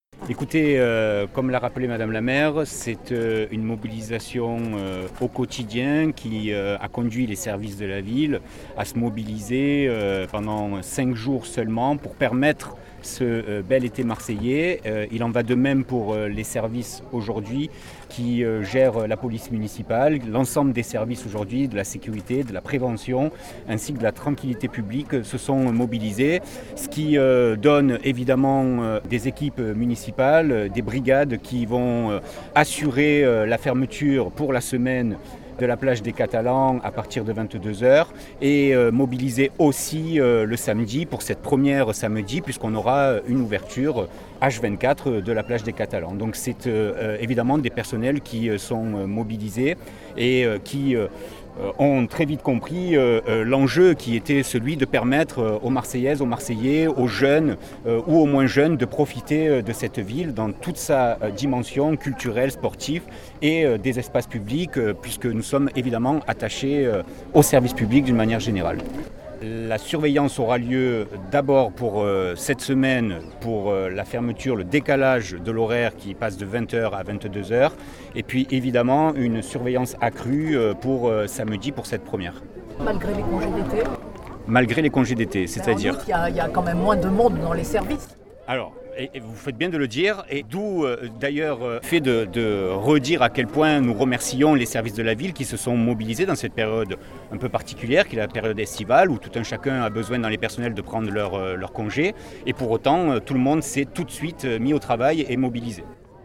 Et cet été qui prend place du 1er août au 23 septembre 2020 vient d’être lancé ce 28 juillet par la maire de Marseille Michèle Rubirola accompagnée par nombre de ses adjoints et un public venu en nombre pour saluer l’initiative…